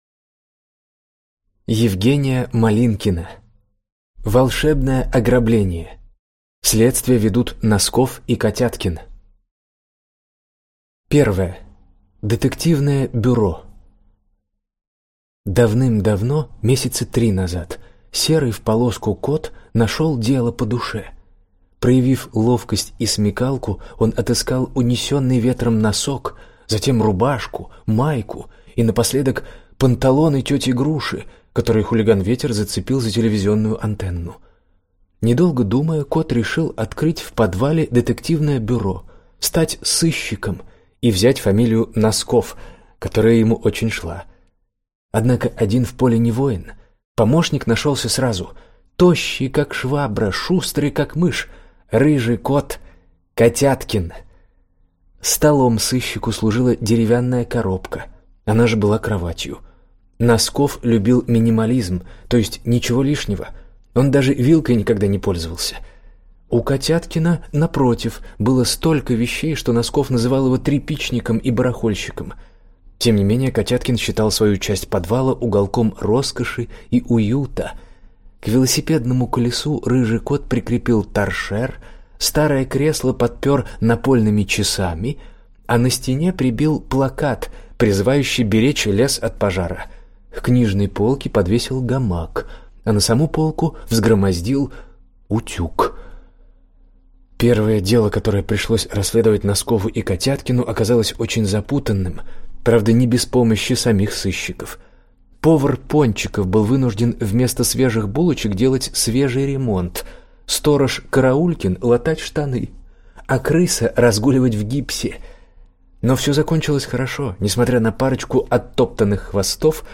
Аудиокнига Волшебное ограбление. Следствие ведут Носков и Котяткин | Библиотека аудиокниг